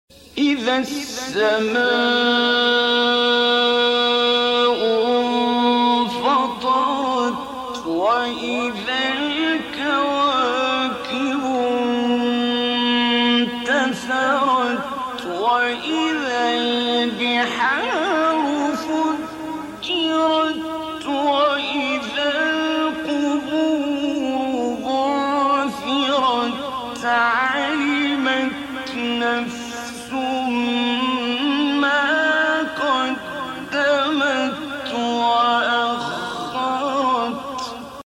سورة_الانفطار#تلاوة_خاشعة#بصوت_الشيخ_عبدالباسط_عبدالصمد